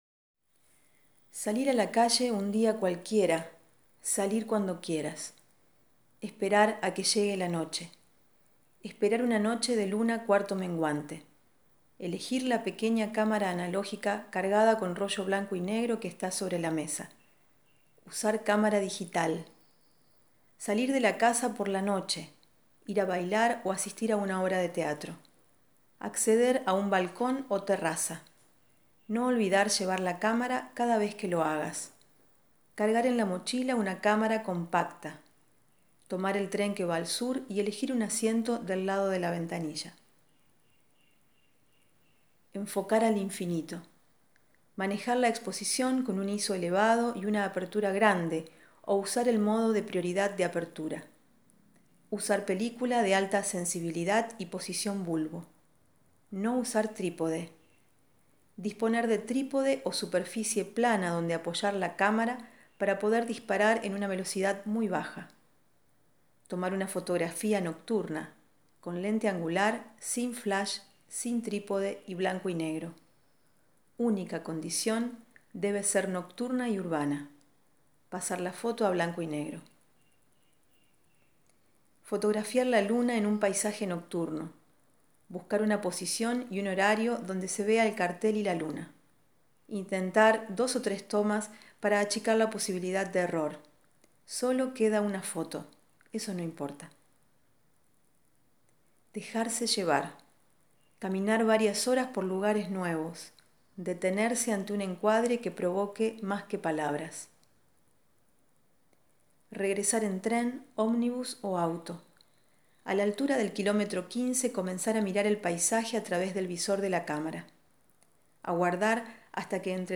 le pone voz a esos textos, que viajan como mensajes de audio por wasapp.